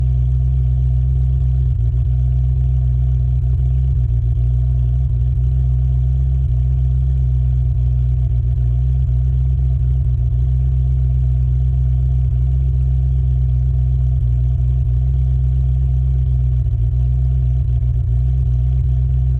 Ferrari_F40_LM_t15_Onbrd_Idle_Steady_Short_Exhaust_Mix.ogg